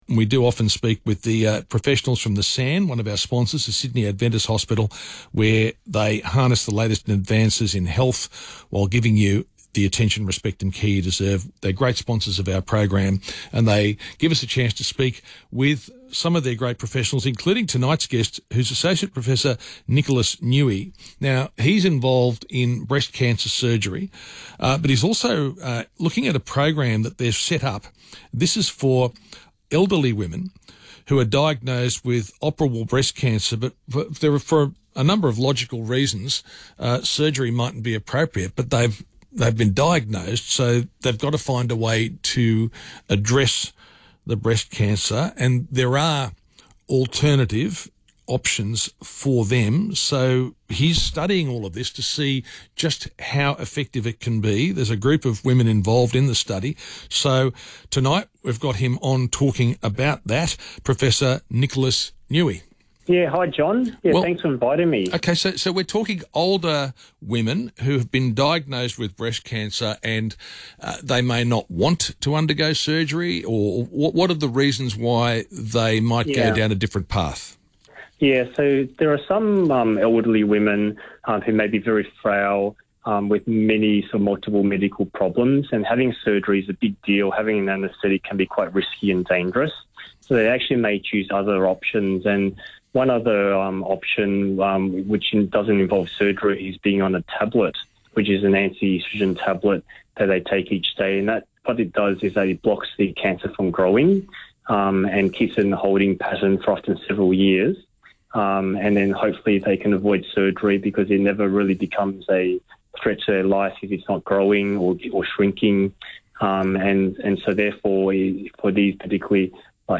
Radio interviews & Mentors in Medicine Podcast
Breast Surgeon